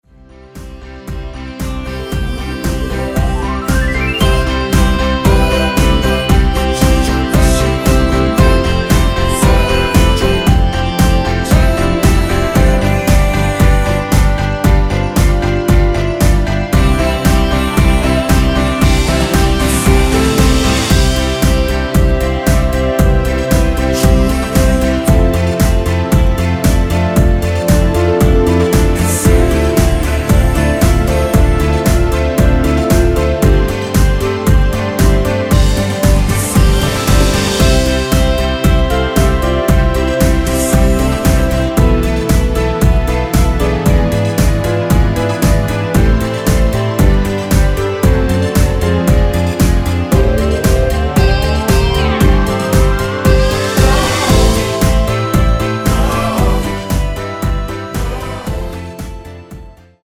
워어~ 등 순수 코러스만 있습니다.
엔딩이 페이드 아웃이라 노래 부르기 좋게 엔딩 만들었습니다.
원키에서(-3)내린 멜로디와 코러스 포함된 MR입니다.
앞부분30초, 뒷부분30초씩 편집해서 올려 드리고 있습니다.
중간에 음이 끈어지고 다시 나오는 이유는